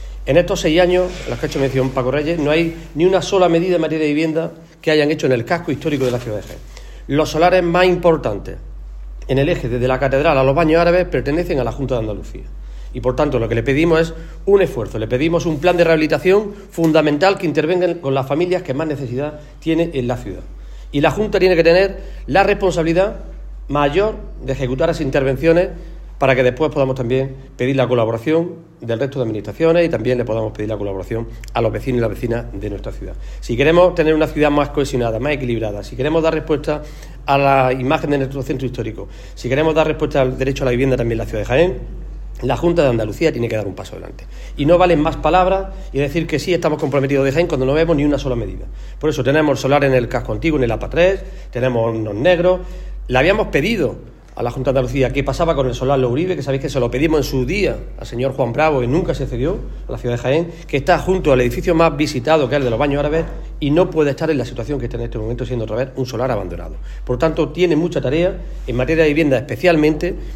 Declaraciones de Reyes y Millán tras reunión del Grupo Parlamentario y del Grupo Municipal
Cortes de sonido
Julio-Millan-reunion-capital.mp3